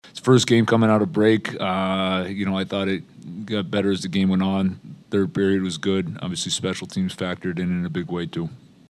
Coach Dan Muse says there was some rust and too much time spent killing penalties, but the Pens got it together in the third period.